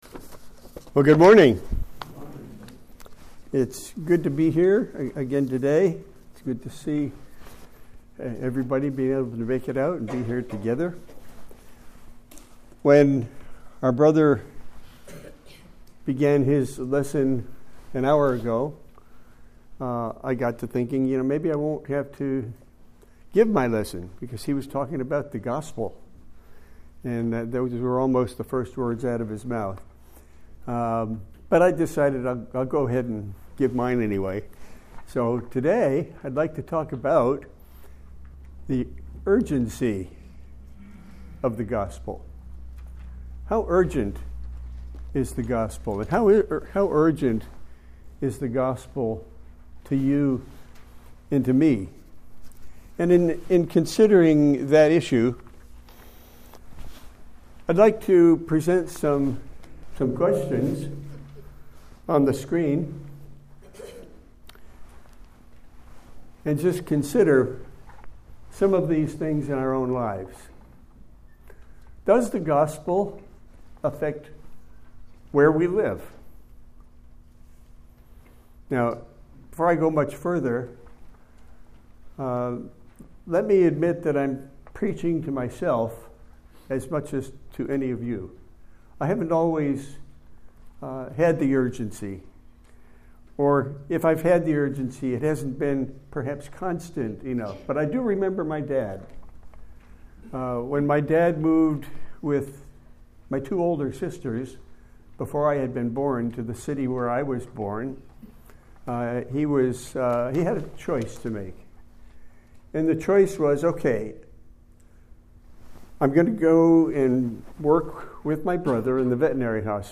This lesson has an accompanying list of scriptures which was provided to the congregation on the day of the presentation.
The following is the audio recording of the lesson itself: